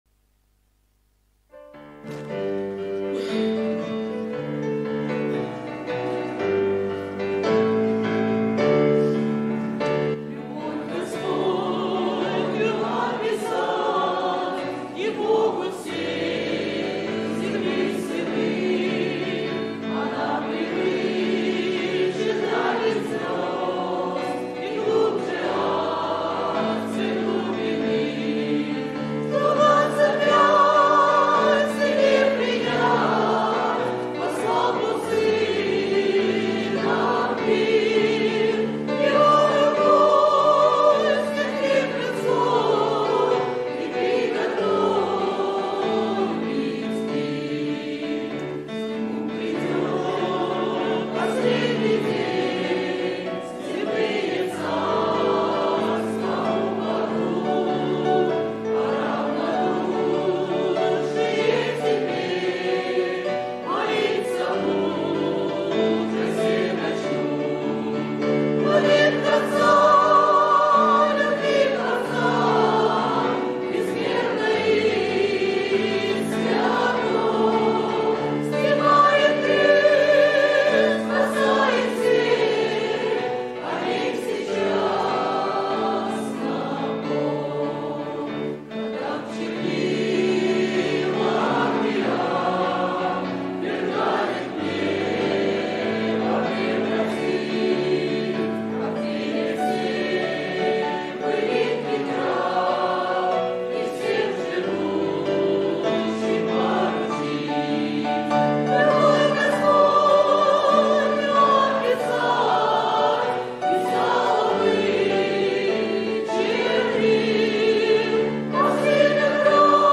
|  Пример исполнения 2 | Общее пение |
Тональность Ми-бемоль мажор